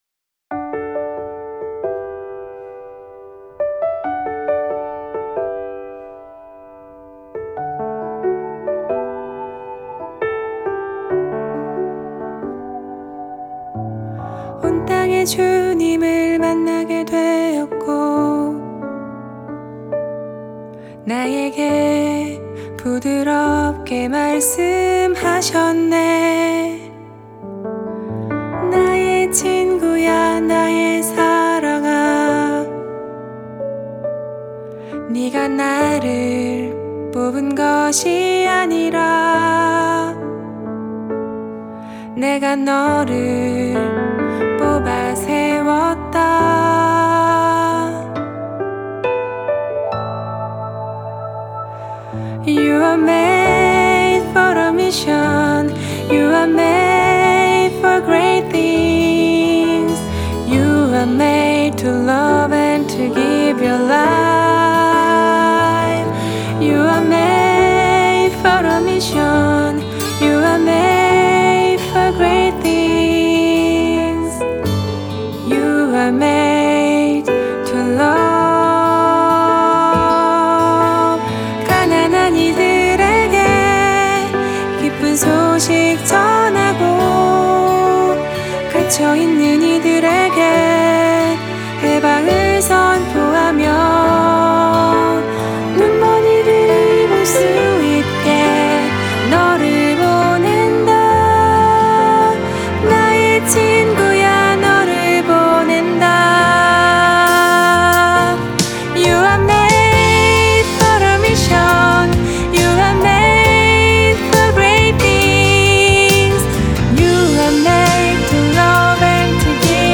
기도와 사목 돌봄을 위한 노래